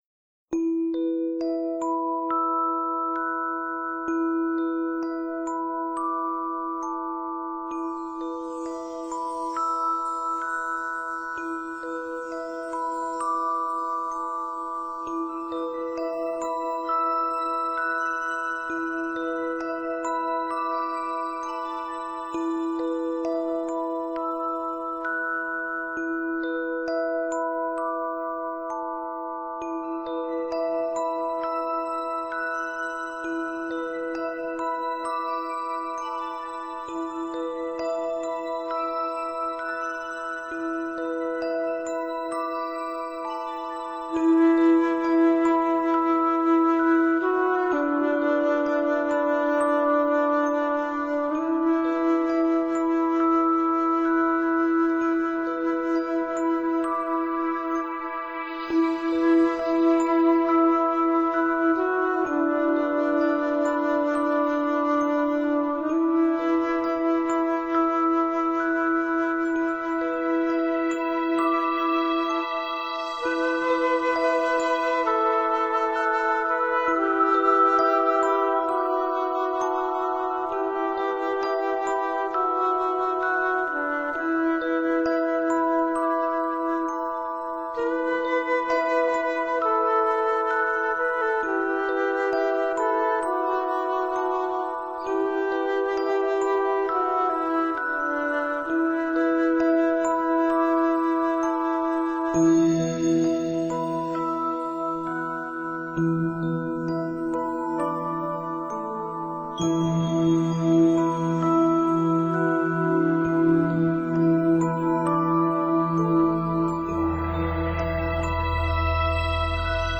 来自内在能量中心最深处的音乐，带给身心最简朴、自然的解放。